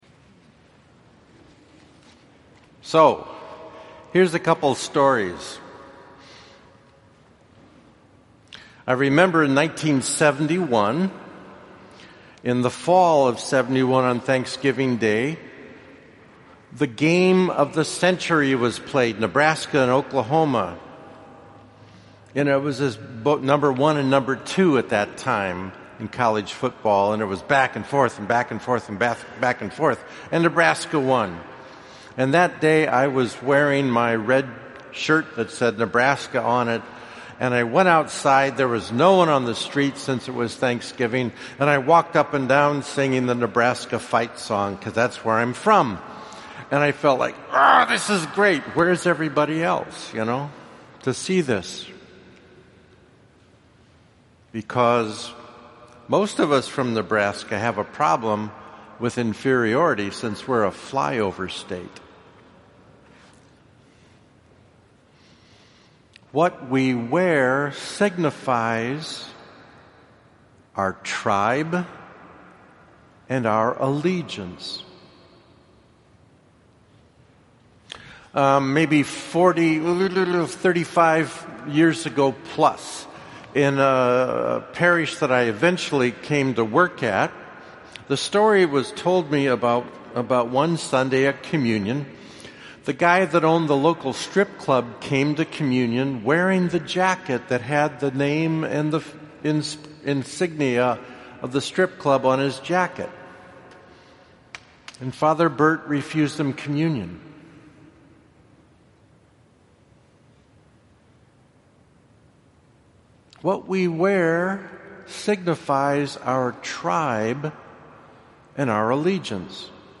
I invite you to give a listen to Sunday's homily.